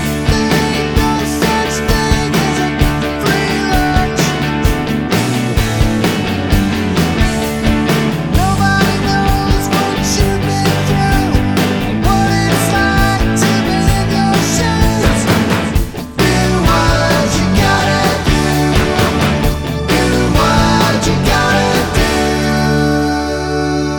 no Backing Vocals Rock 2:13 Buy £1.50